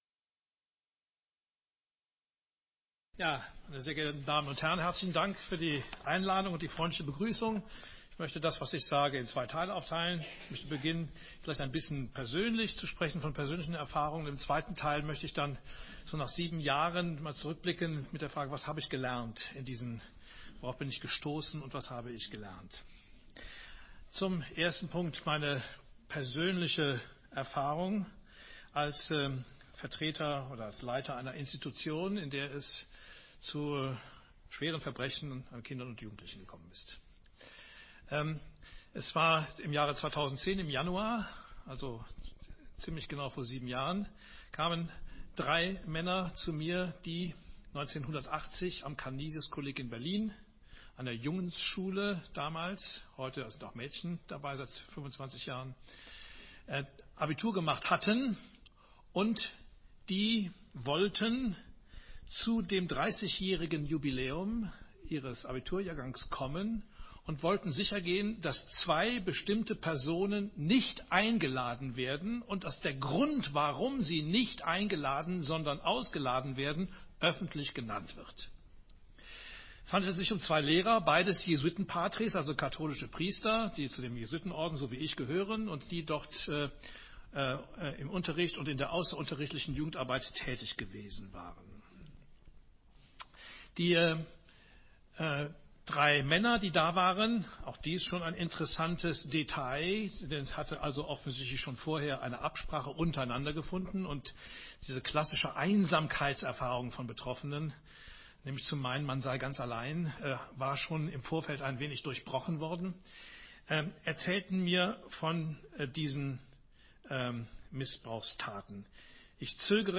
Pater Klaus Mertes, Direktor des Kollegs St. Blasien im Schwarzwald, Vortrag: „Trauma und Institution“ – Gedanken über traumatisierende und traumatisierte Institutionen am 22. Januar 2018 (Audiobeitrag):